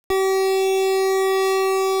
square_pitch_bad.wav